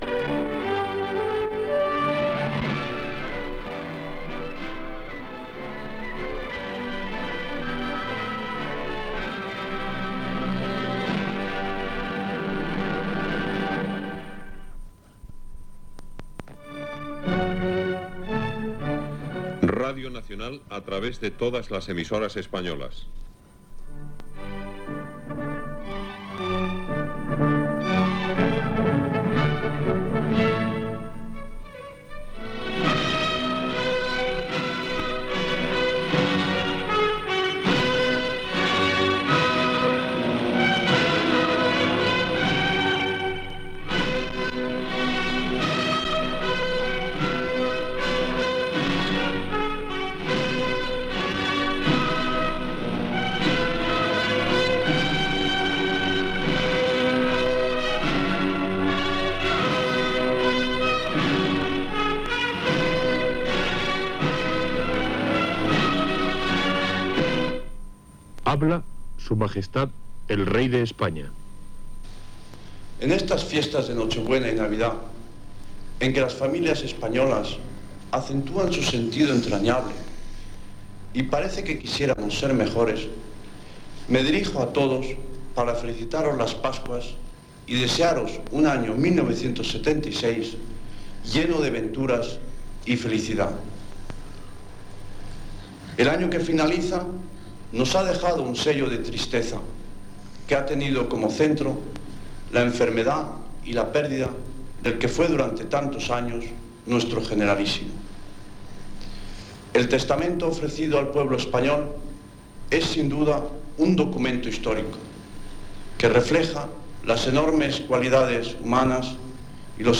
Sintonia de l'emissora, identificació, himne d'Espanya, primer missatge de Nadal del rei Juan Carlos I: lloa al general Francisco Franco, mort aquell any, l'amor cristià, la necessitat de la bona voluntat i la unitat
Informatiu